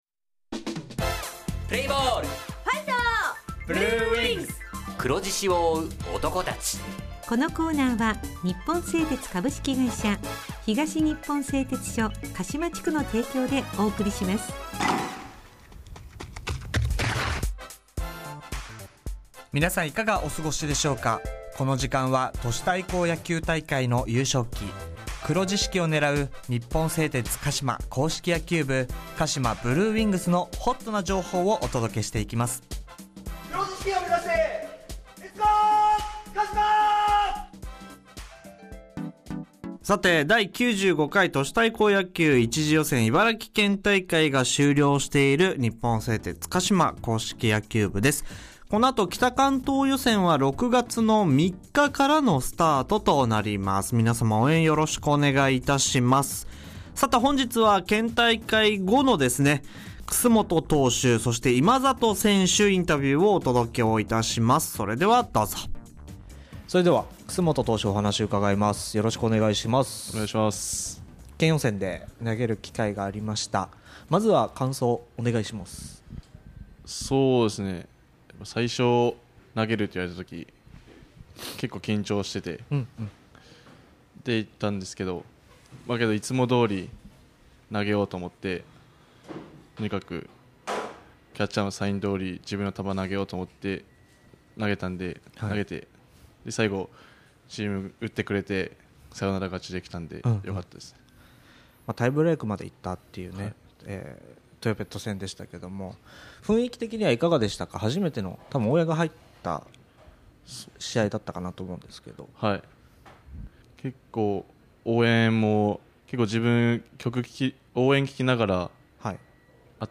選手インタビュー
地元ＦＭ放送局「エフエムかしま」にて鹿島硬式野球部の番組放送しています。